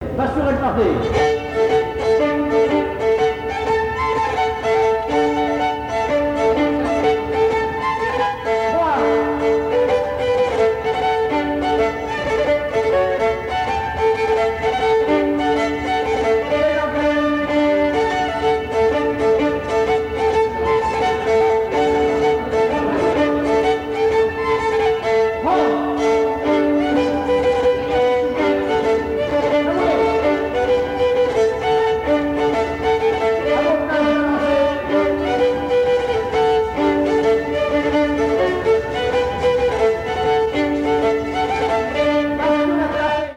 danse : quadrille : pastourelle
Pièce musicale inédite